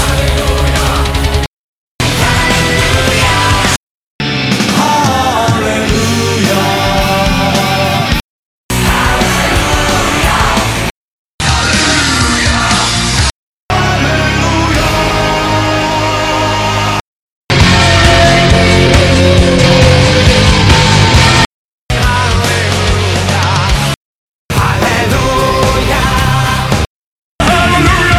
- Die Bands sind alphabetisch sortiert.